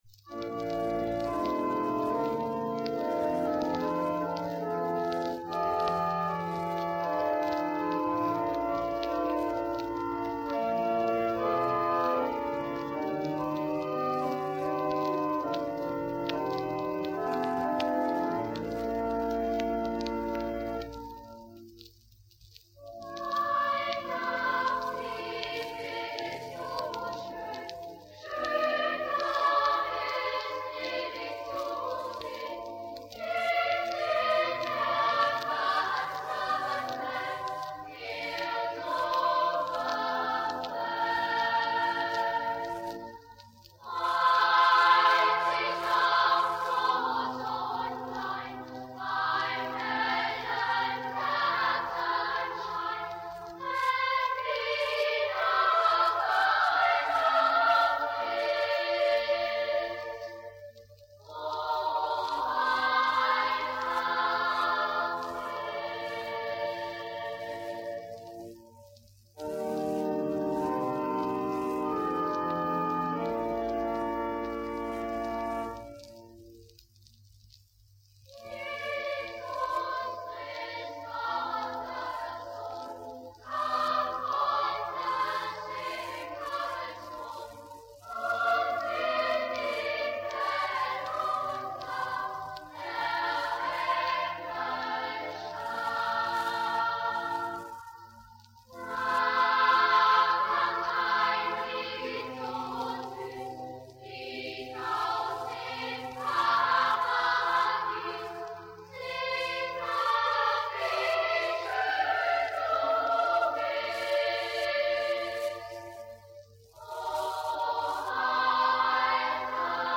Etwa Mitte der 50er Jahre wurde im Hamelner Münster eine Schallplatte mit zwei wunderschönen Weihnachtsliedern aufgenommen.
Bei der Qualität beachten Sie bitte, dass es sich um Schallplattenaufnahmen aus den 50-er Jahren handelt.
Wir haben die Aufnahmen bereits - soweit möglich - aufgearbeitet.